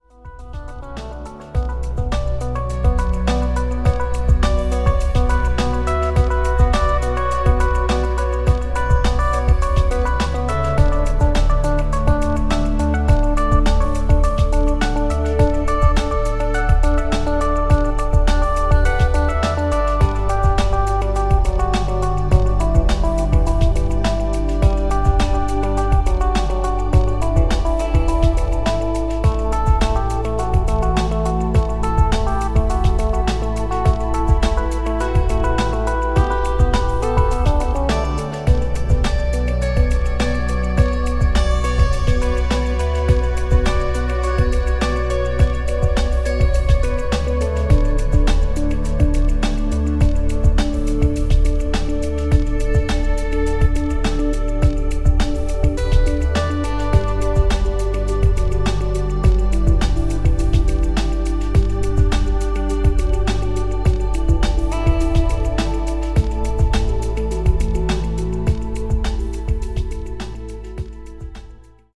The audio is treated by Ozone.